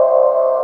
qbuzz.wav